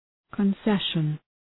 {kən’seʃən}